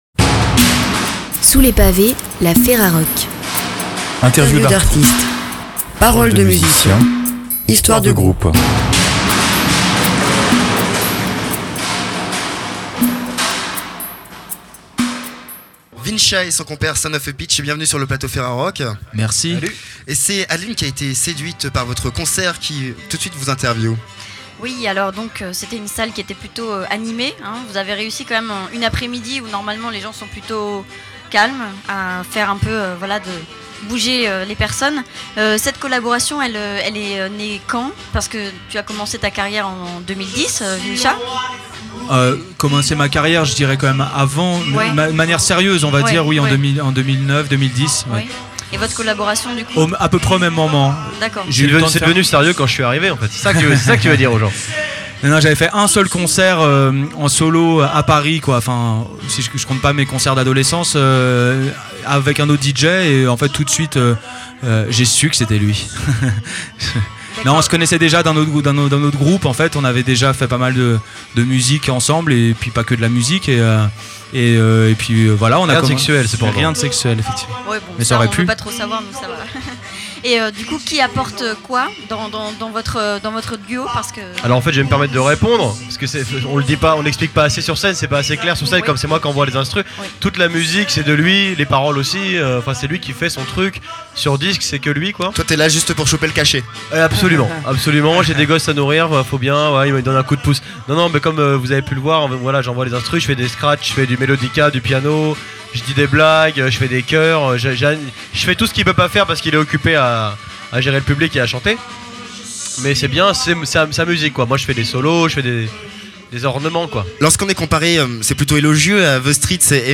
Vous êtes l'artiste ou le groupe interviewé et vous souhaitez le retrait de cet interview ? Cliquez ici.